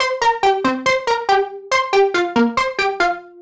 Index of /musicradar/french-house-chillout-samples/140bpm/Instruments
FHC_Arp A_140-C.wav